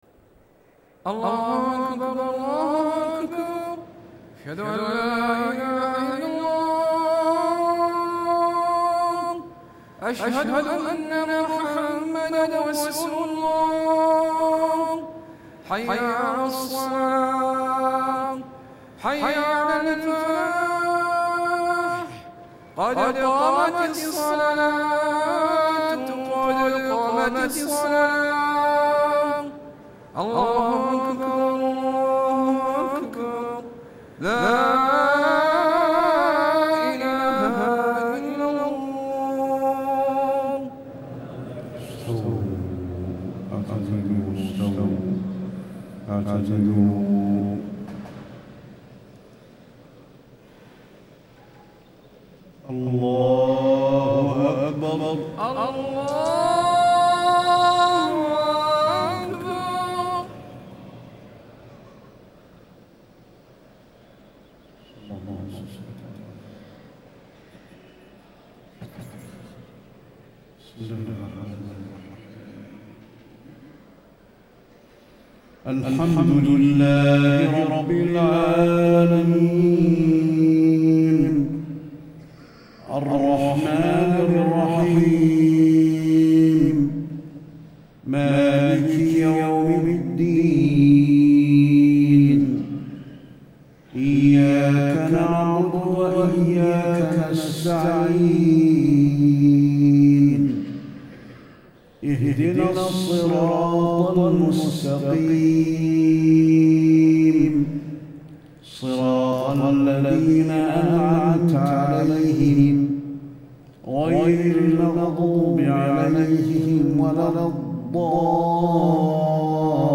صلاة المغرب 3-6- 1435 سورتي الفلق والناس > 1435 🕌 > الفروض - تلاوات الحرمين